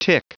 Prononciation du mot tick en anglais (fichier audio)
Prononciation du mot : tick